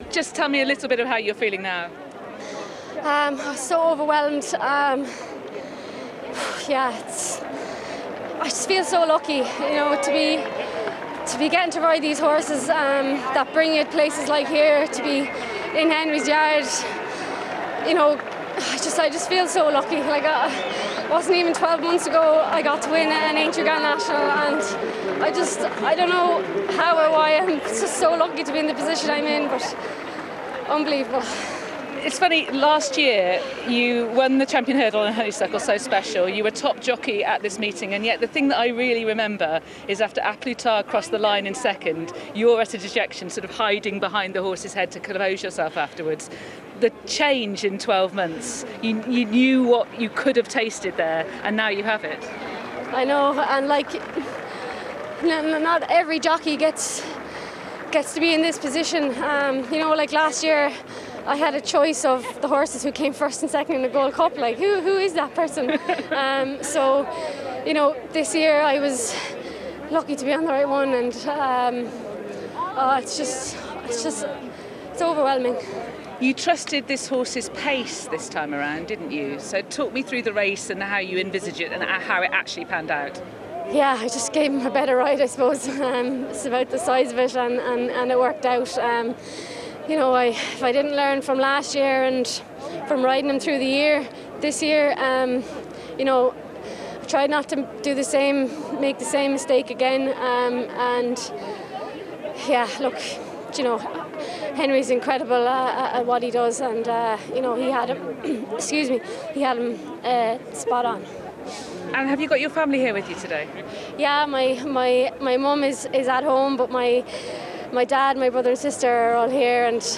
She told Racing TV she feels so lucky the past year, following today’s Gold Cup win and the Aintree Grand National.